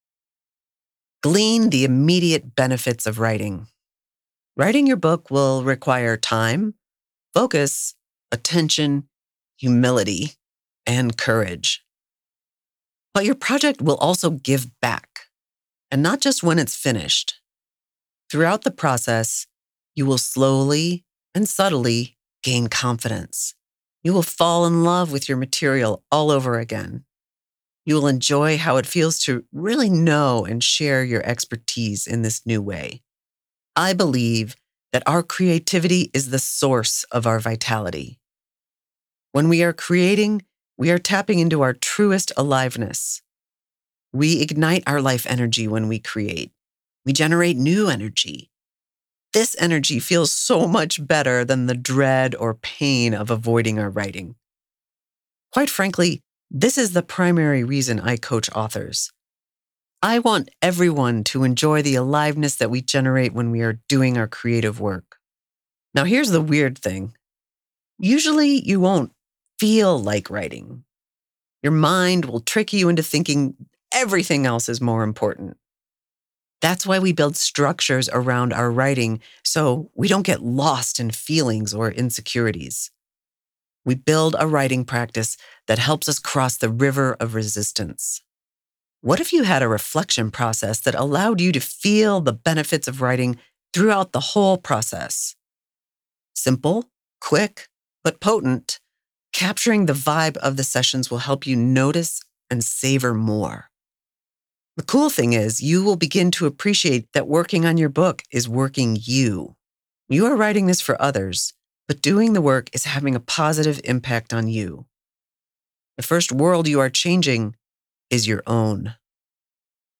Are you an audio book listener?